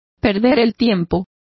Complete with pronunciation of the translation of loitering.